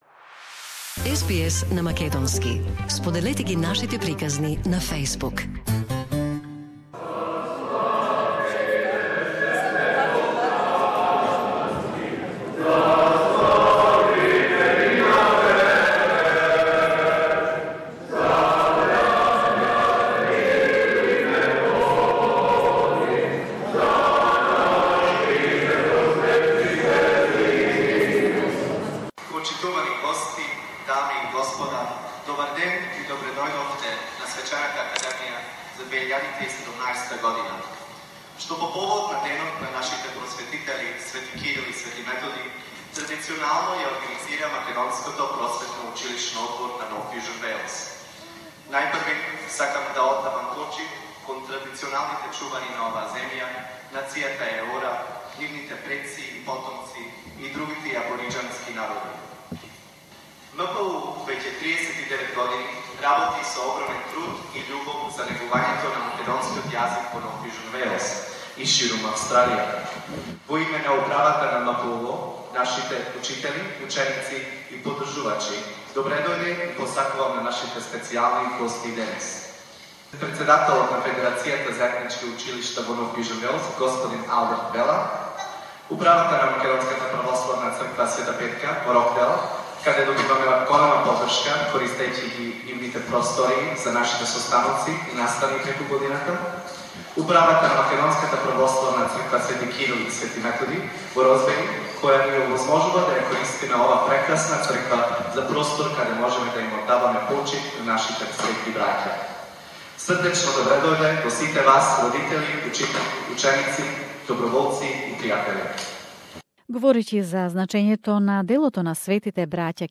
Macedonian language students from the ethnic and mainstream primary and secondary, saturday schools, their teachers and parents attended the ceremony held in the church "St Cyril and Methodius" in Sydney to honour the glorious brothers Cyril and Methodius.The event was organised by the Macedonian Educational Board of NSW